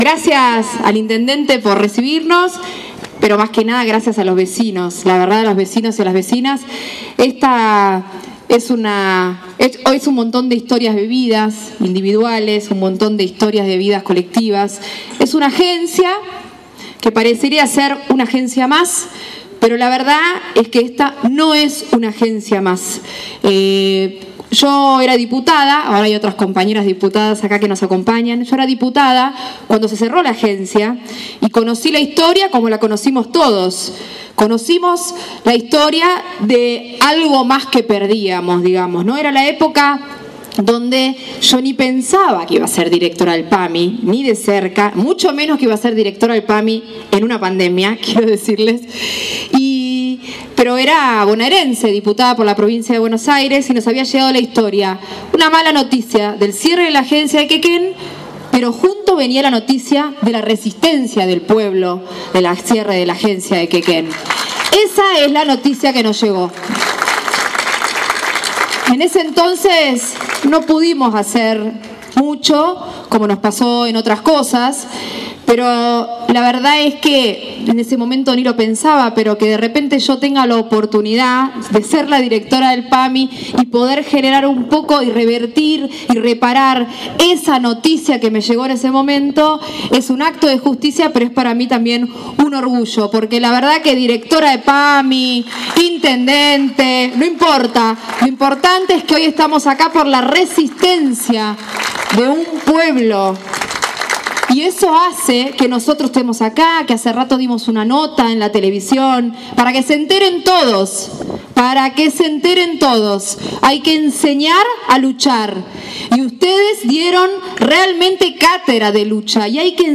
Para tal fin, se celebró un acto al aire libre en las puertas donde funciona la agencia, ubicada en calle 554 Nº 884, al que acudió especialmente la directora de la prestadora de asistencia médica a nivel nacional, Luana Volnovich.